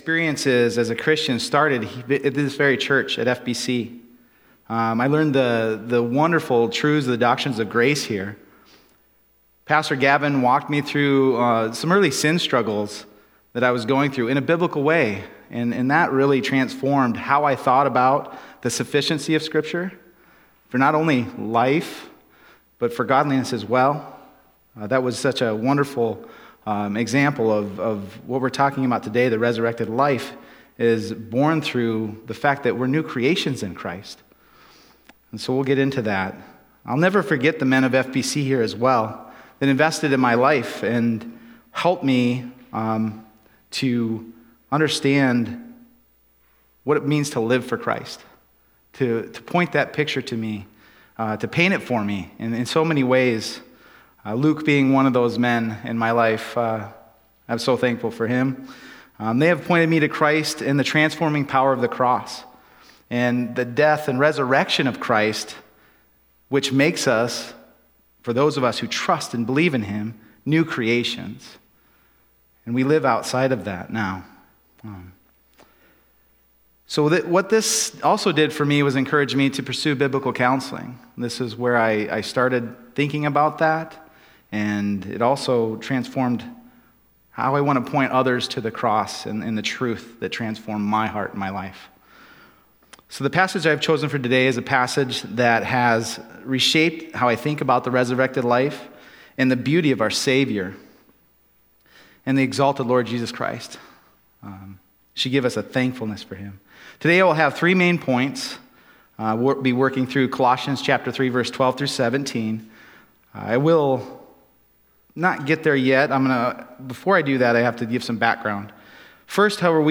Passage: Colossians 3:12-17 Service Type: Sunday Morning